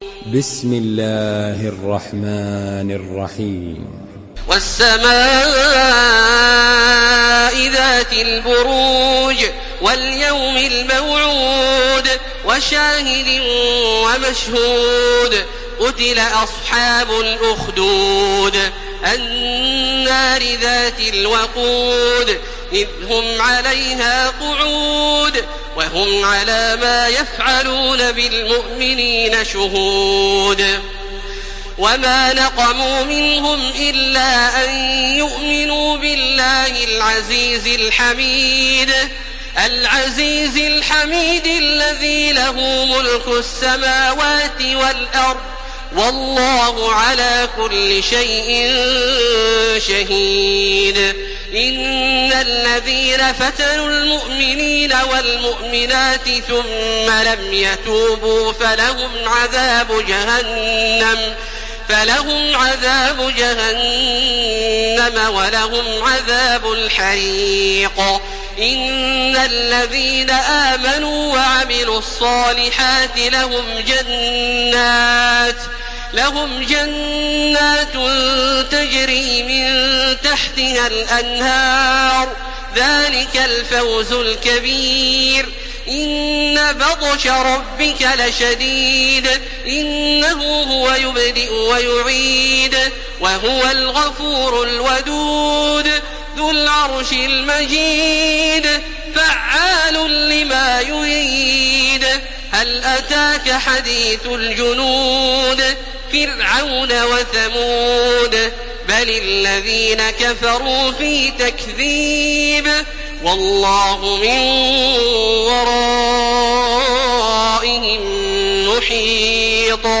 Surah Büruc MP3 by Makkah Taraweeh 1431 in Hafs An Asim narration.
Murattal Hafs An Asim